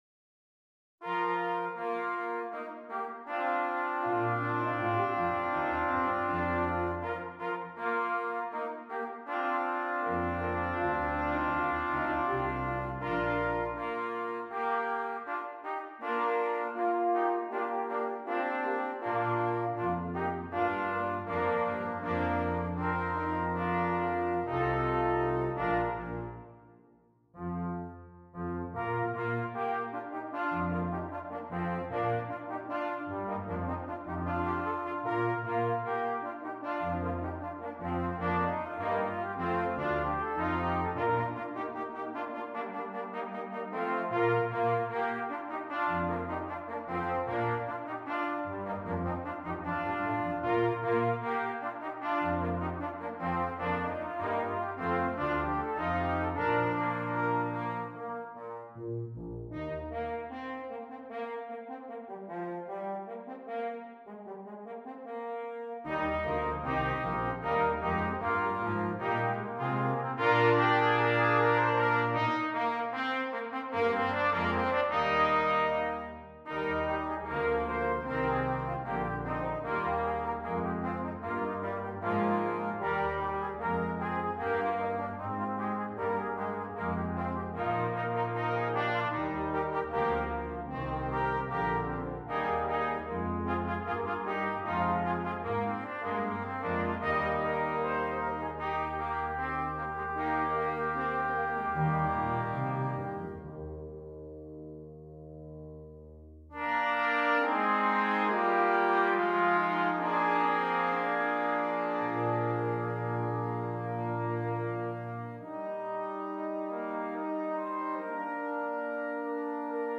Brass Quintet (optional Percussion)